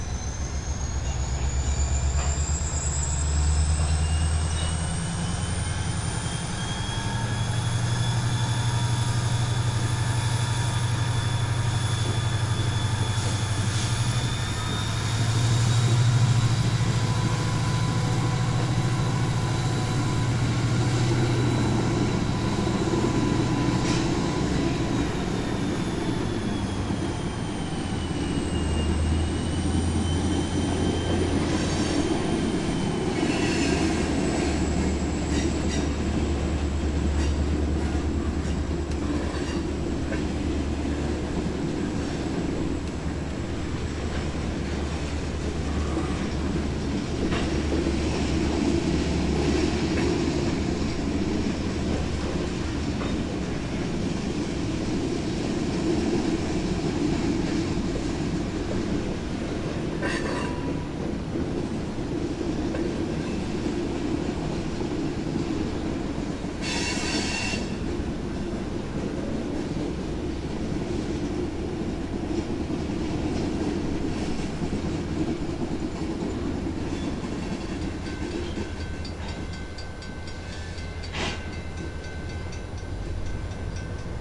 交通运输 " 农场氛围机车经过的地方
描述：机车缓缓通过。环境分级场
标签： 分类 机车 铁路
声道立体声